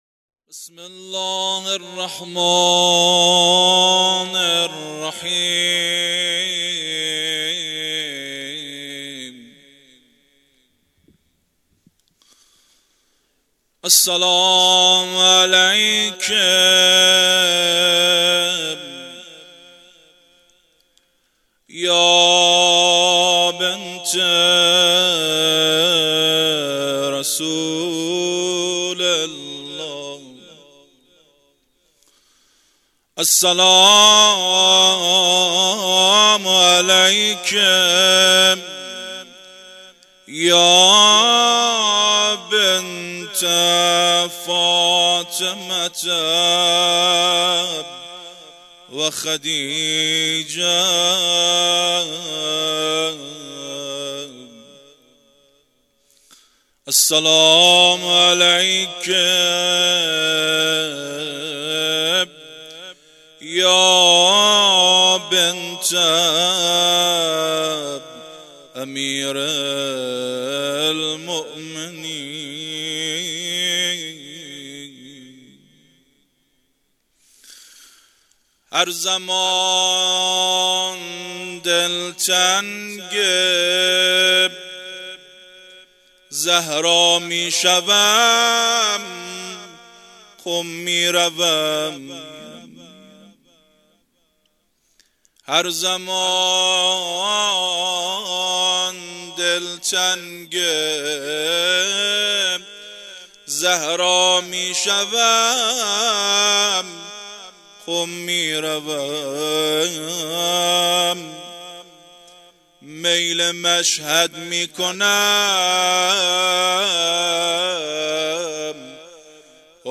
هیئت مکتب الزهرا(س)دارالعباده یزد - روضه | هر زمان دل تنگ زهرا میشوم مداح